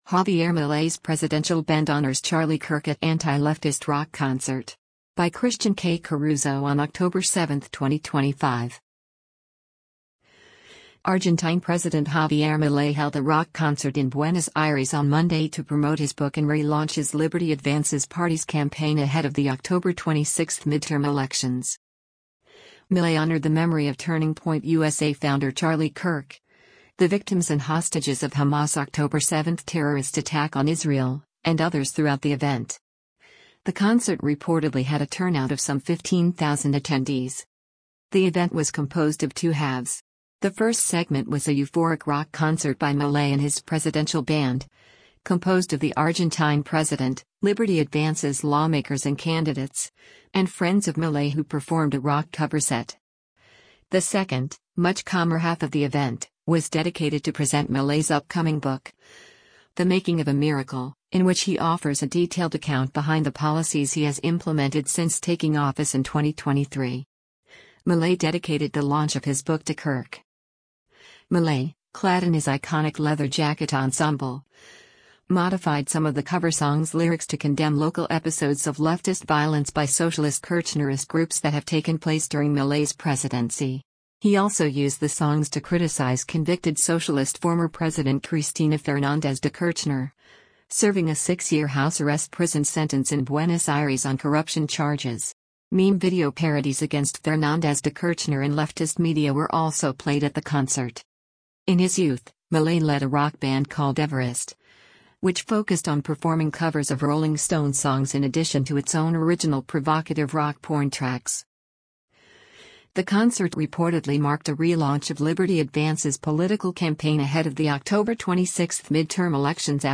rock cover set